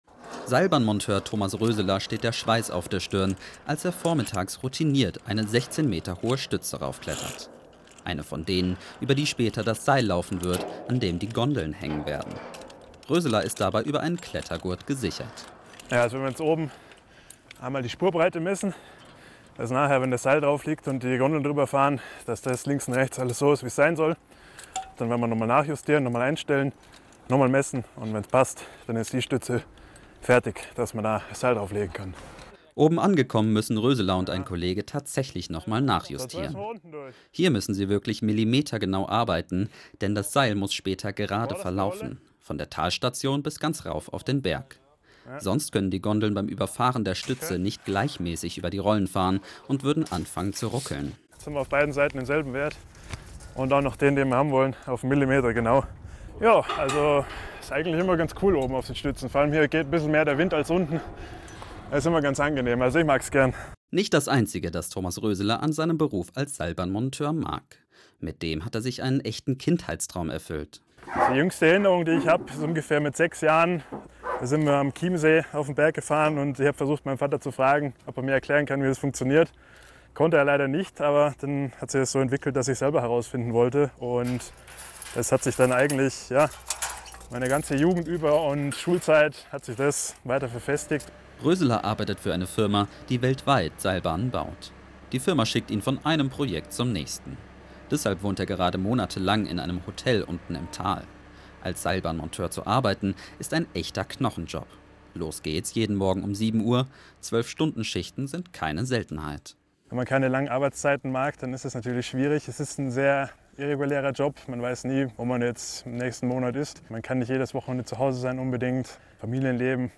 Zusätzlich haben wir für das Bayern 2 Rucksackradio Beiträge produziert, die von unseren „Wie werde ich...?“-Berufen am Berg erzählen.